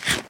eat1.ogg